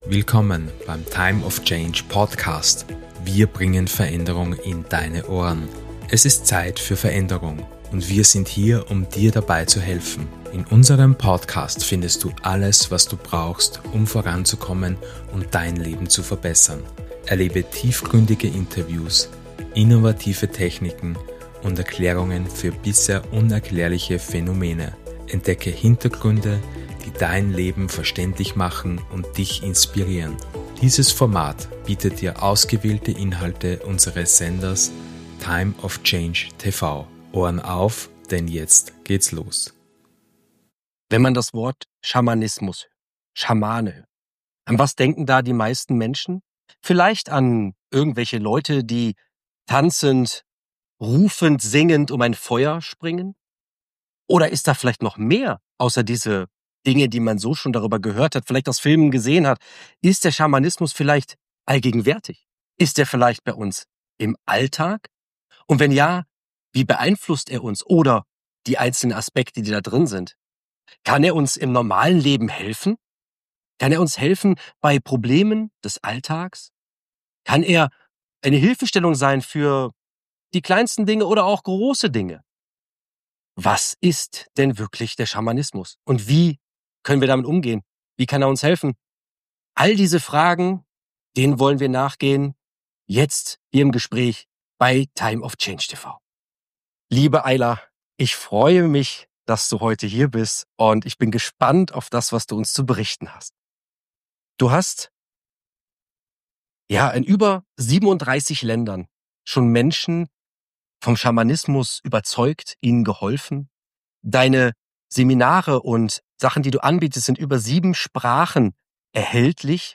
Das Interview schließt mit einer kraftvollen schamanischen Trommelzeremonie, die Dich in die energetische Welt des Schamanismus eintauchen lässt.